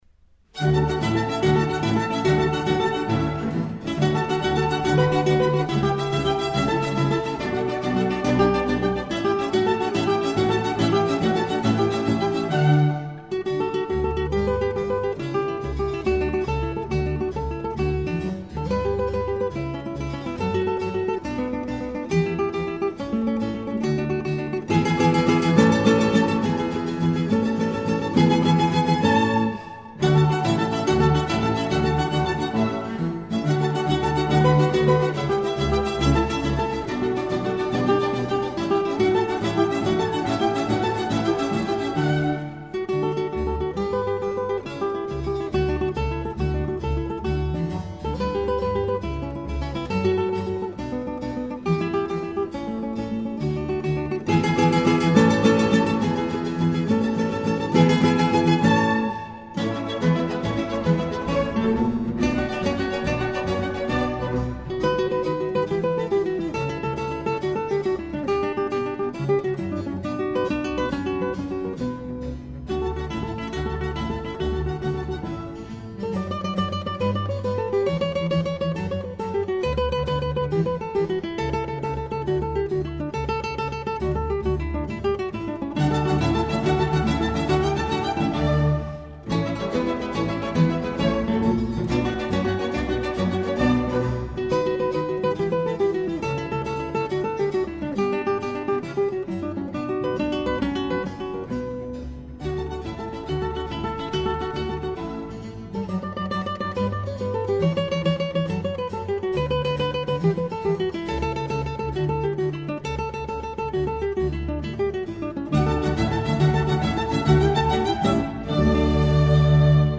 gitara
klawesyn